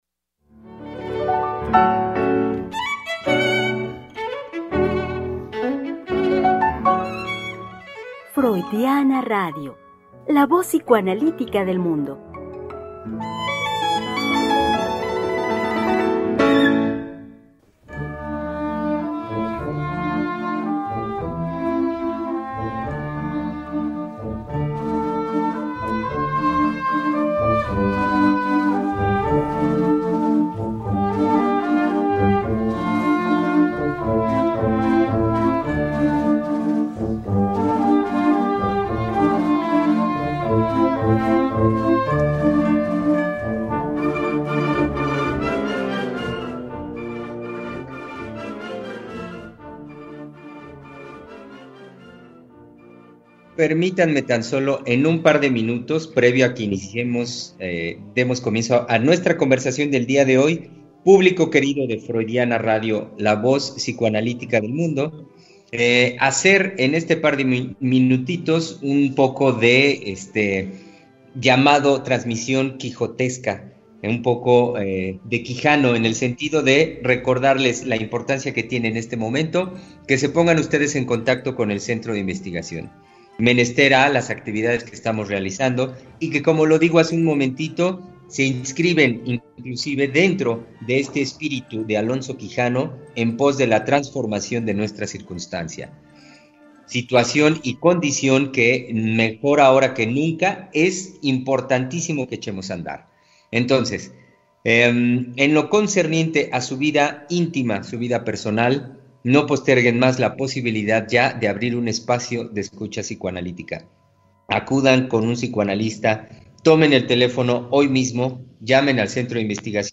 Conversación con nuestros invitados los psicoanalistas del CIEL.